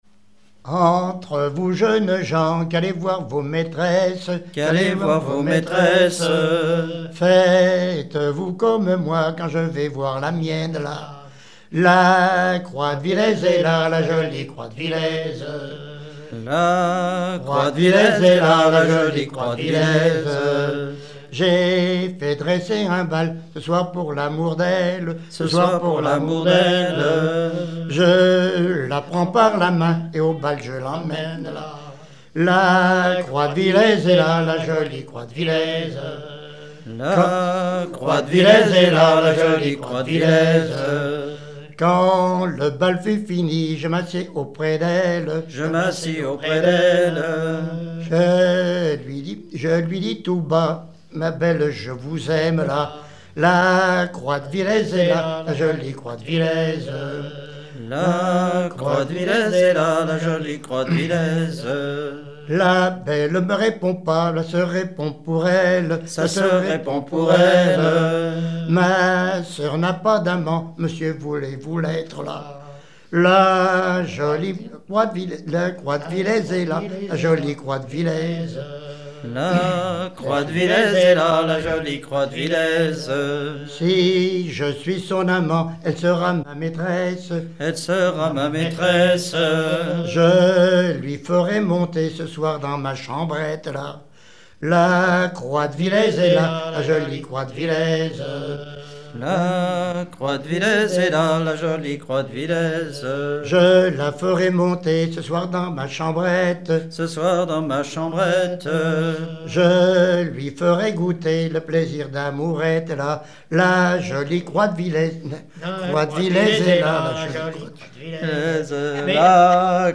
gestuel : à haler
circonstance : maritimes
Pièce musicale inédite